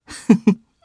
Fluss-Vox-Laugh_jp.wav